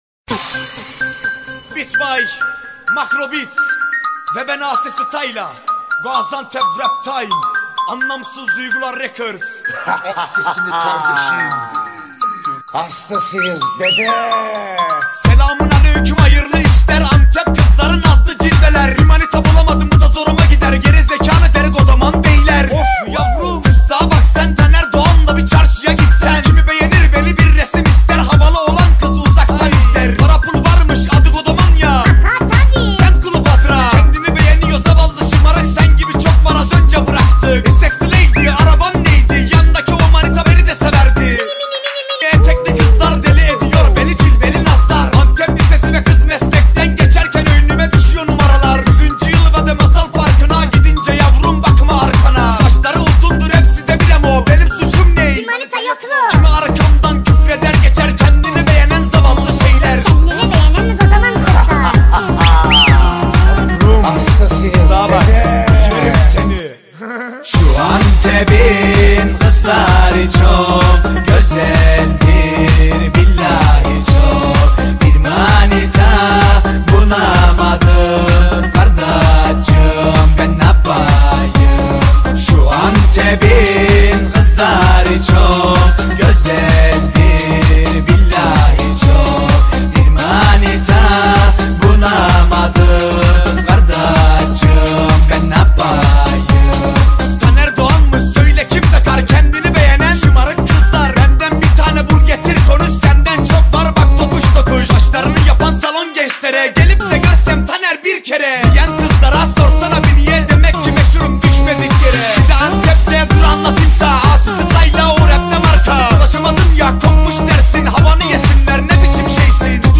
رپ ترکی فاز بالا